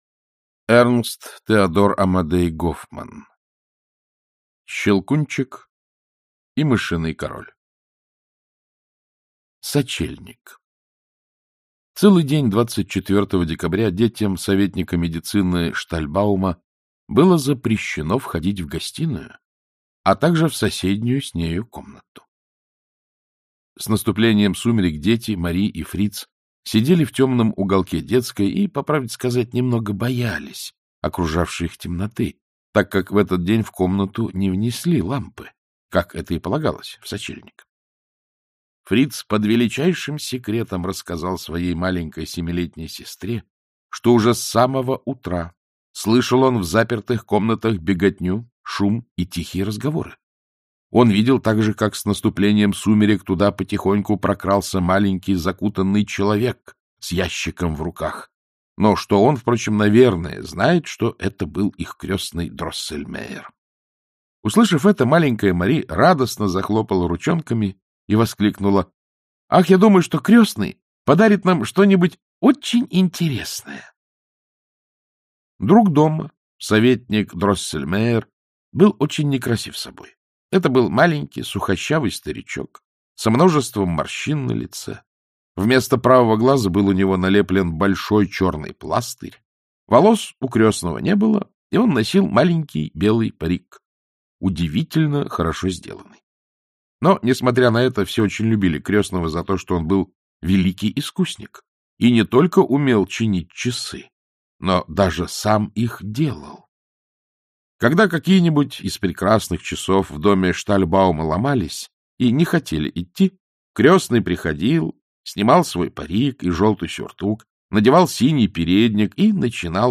Аудиокнига Щелкунчик | Библиотека аудиокниг